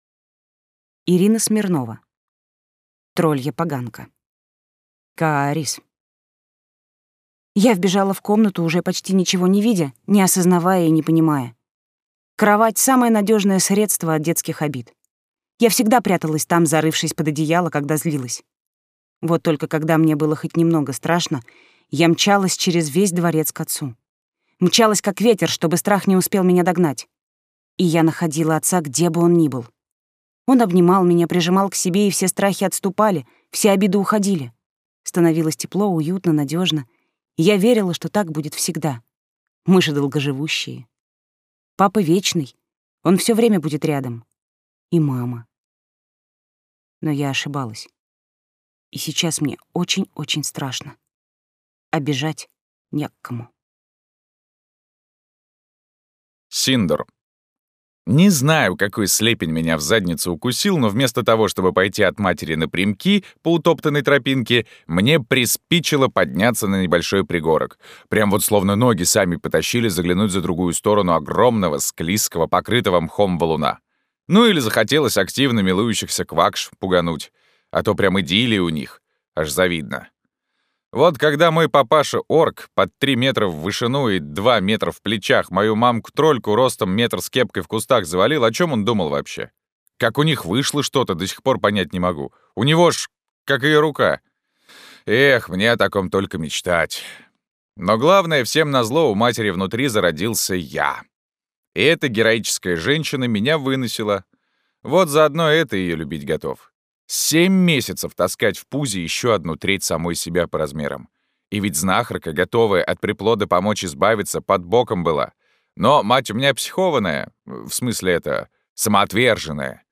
Аудиокнига Троллья поганка | Библиотека аудиокниг